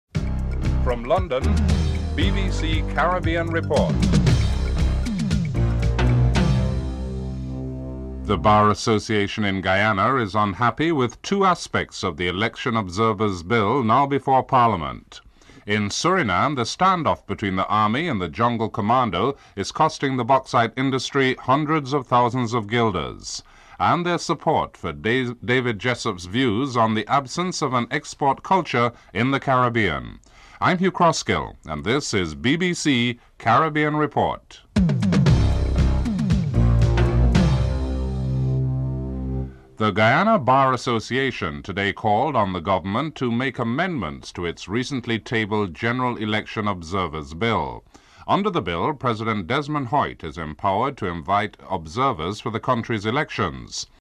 1. Headlines (00:00-00:38)